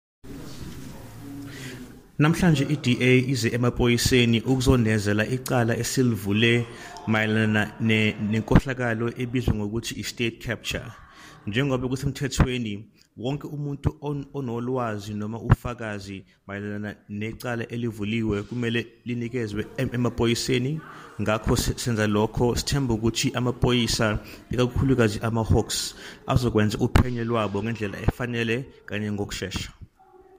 isiZulu soundbite by Mr Mbhele
Zak-Mbhele-Zulu.mp3